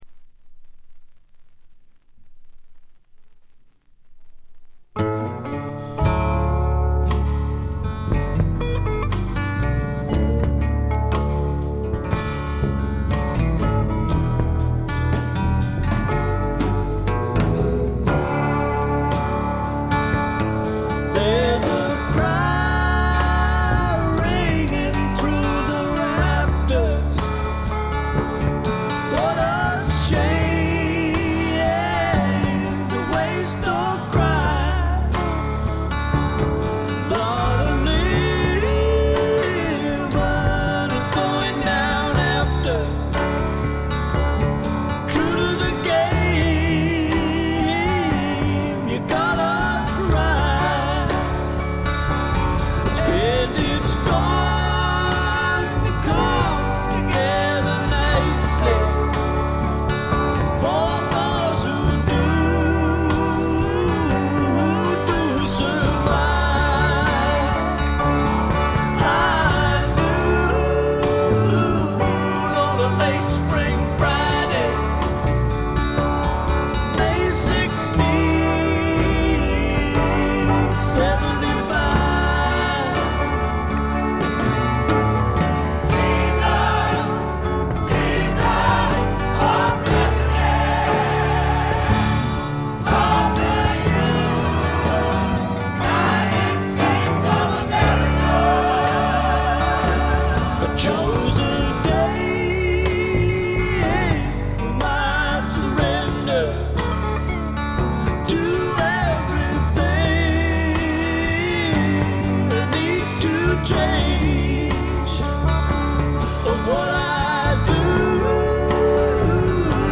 わずかなノイズ感のみ。
泣けるバラードからリズムナンバーまで、ゴスペル風味にあふれたスワンプロック。
試聴曲は現品からの取り込み音源です。
Backing Vocals